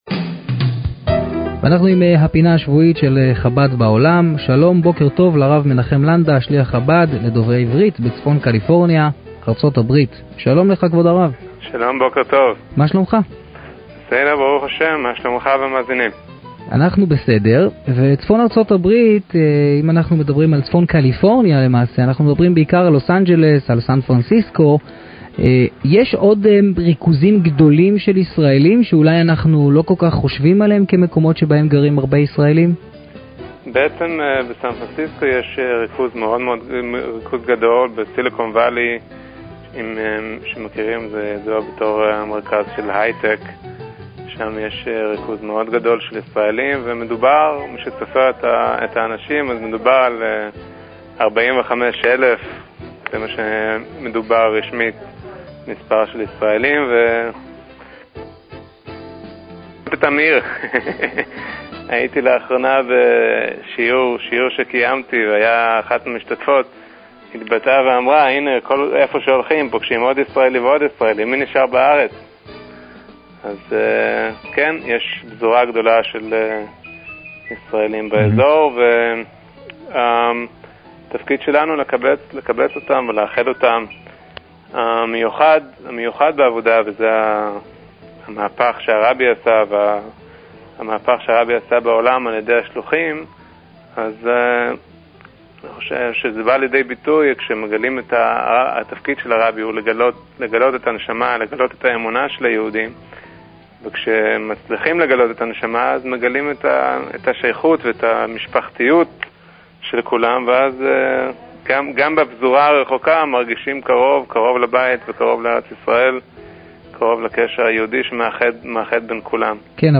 ראיון עם שליח חב"ד, בתכנית "קול שישי"